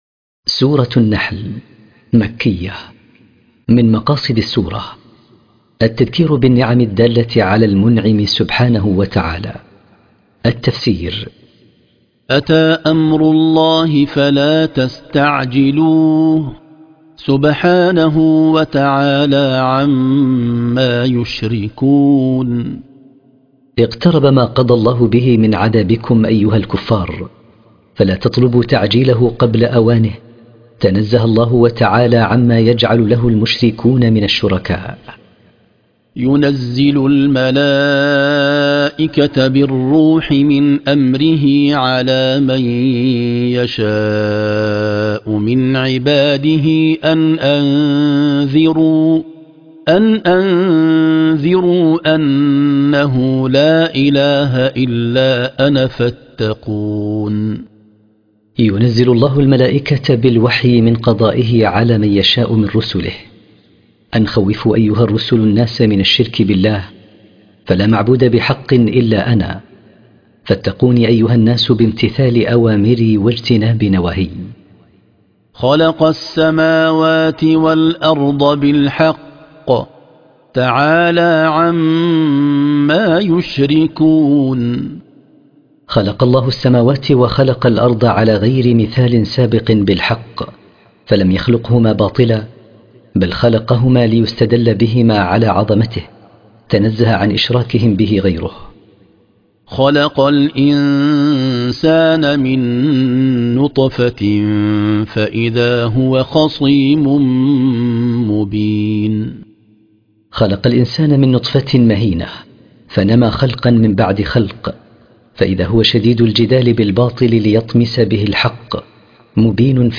قراءة تفسير سورة النحل من كتاب المختصر